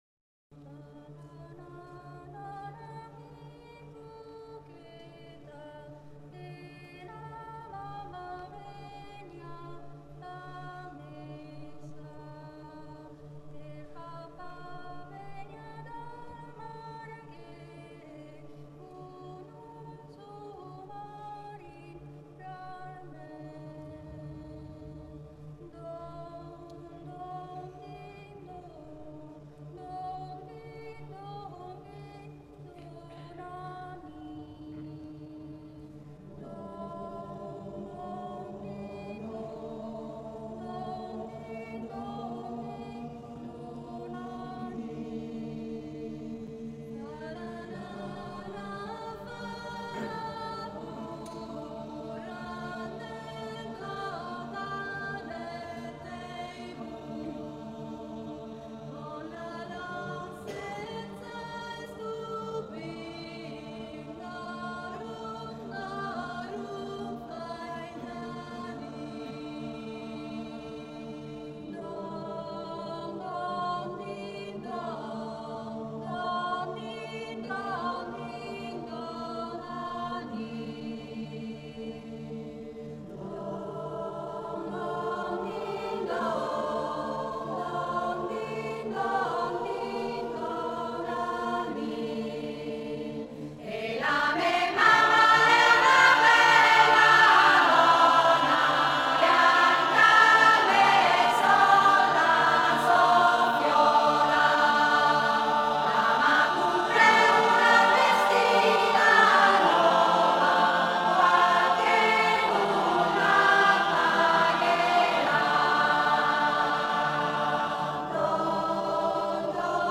Coro popolare canti Emiliani